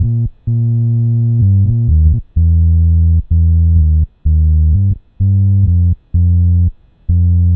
Track 10 - Bass 01.wav